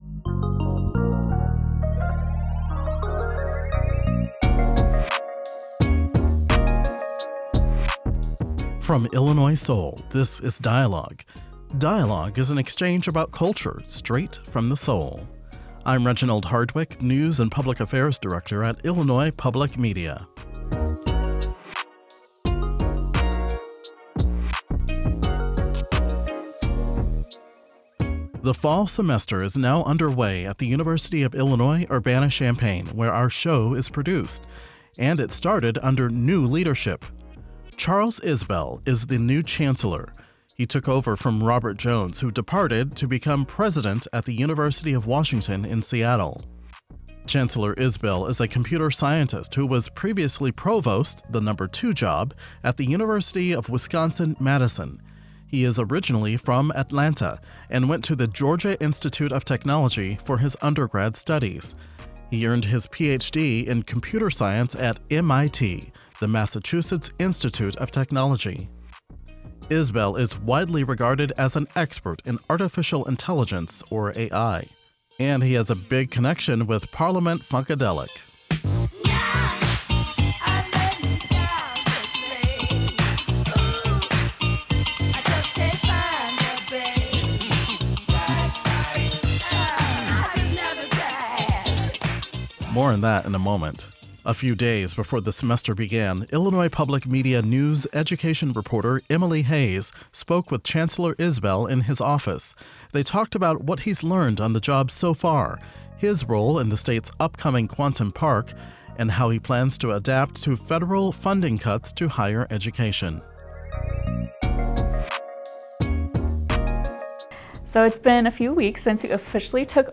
__ GUEST: Charles Isbell University of Illinois Chancellor